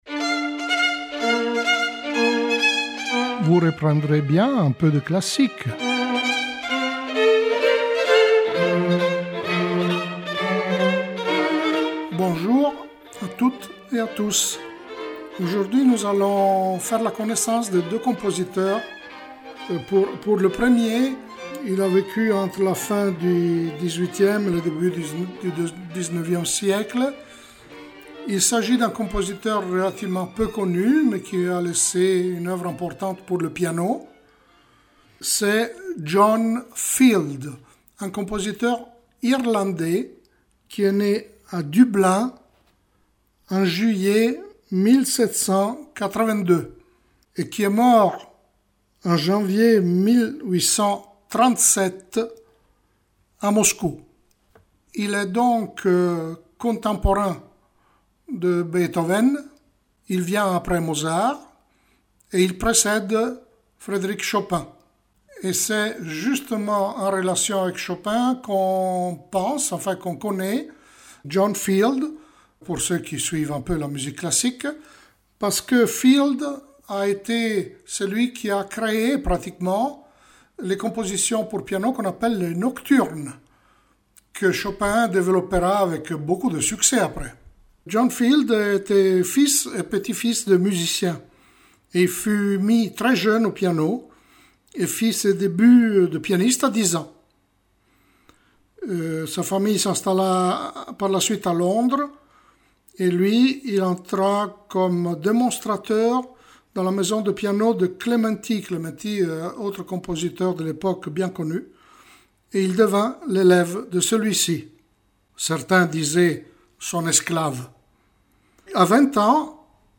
piano
Piano Concerto No.3 en mi bémol majeur
Chaconne à 4 en sol majeur Fantasia en sol mineur The Harmonious Society of Tickle-Fiddle Gentlemen dir.
musique classique Laisser un commentaire Laisser un commentaire Annuler la réponse Votre adresse e-mail ne sera pas publiée.